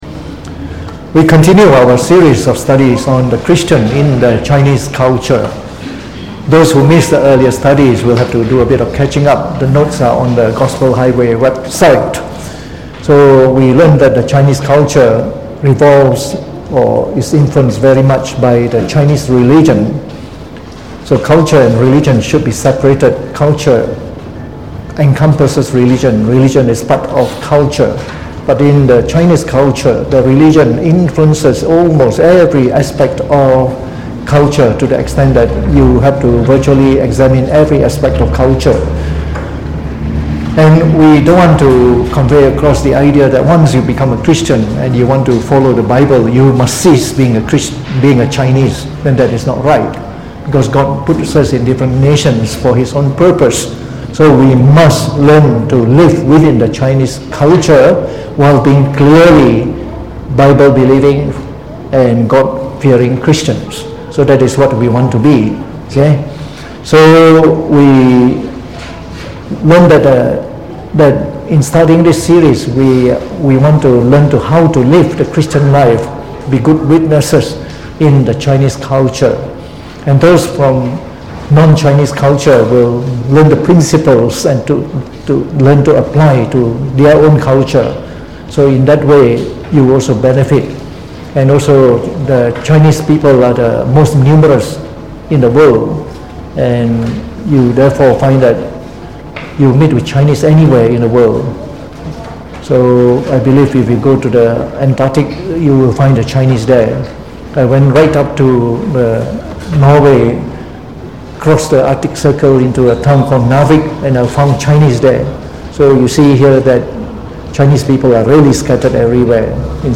Delivered on the 21th of August 2019 during the Bible Study, from the series on The Chinese Religion.